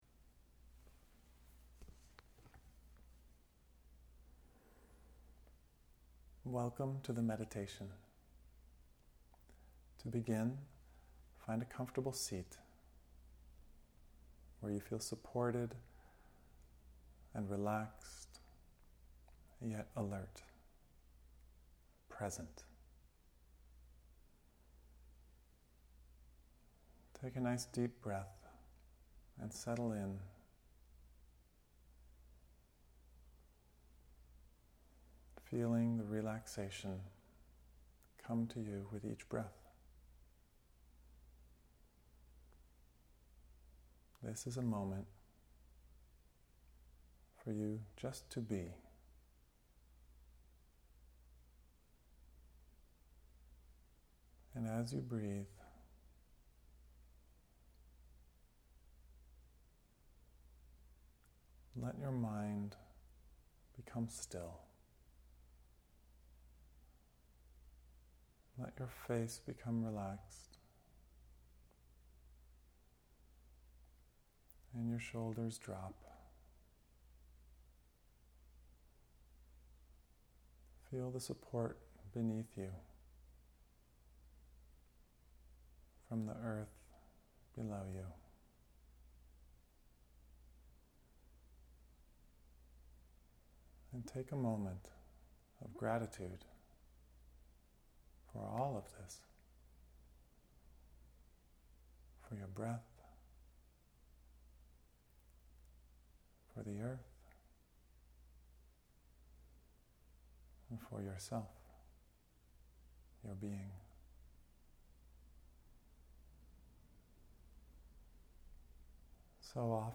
Meditation for Presence